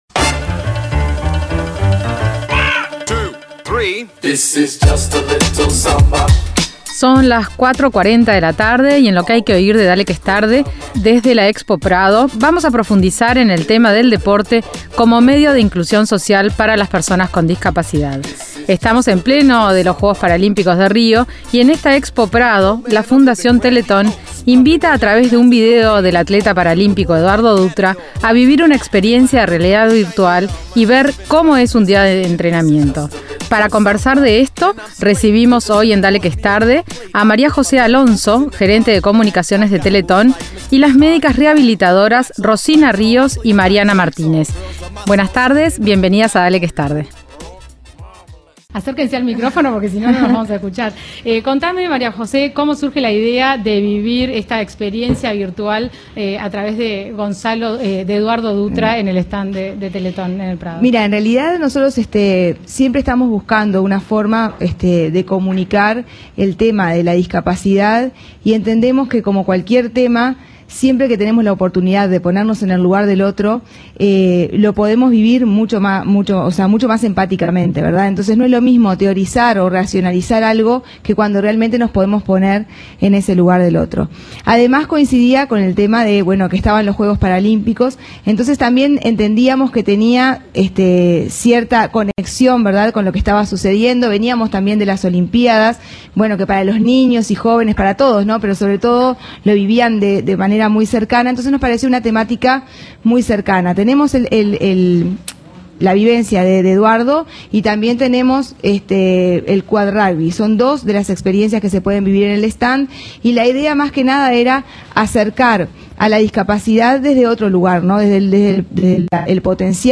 En "Lo que hay que oir" de Dale que es Tarde, desde la Expo Prado 2016, profundizamos en el deporte y la actividad física como medio de inclusión social para las personas con discapacidad.